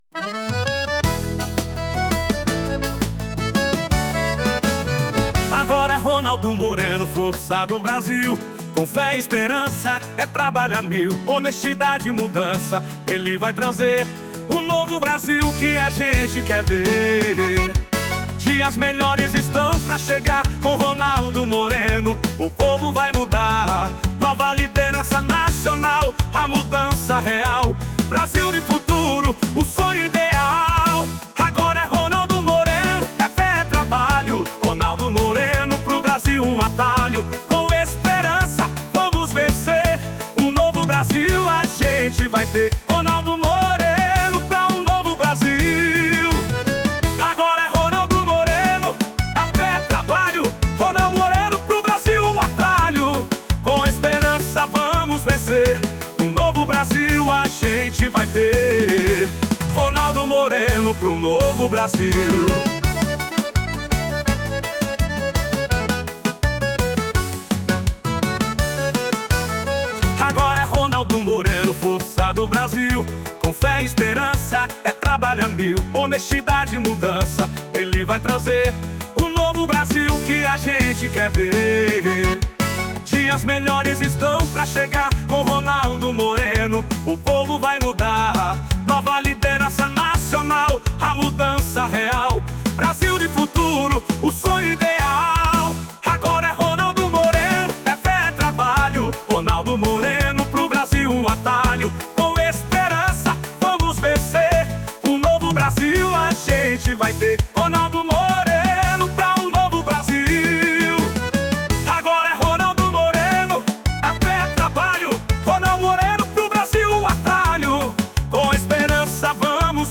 Jingle sob título